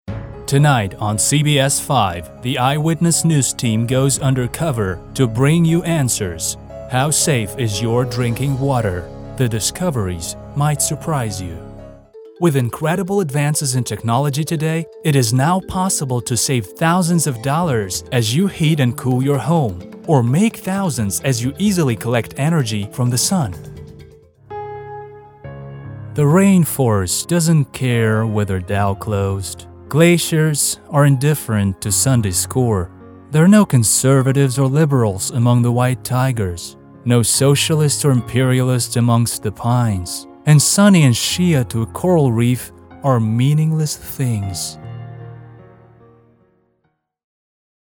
Очень мягкий и приятный на слух англоязычный голос.
Родной американский английский, но способен работать на британском и других акцентах.
Focusrite Scarlett 18i20, Fostex PM0.5D MkII, Neumann TLM 102, Audio-Technica AT4040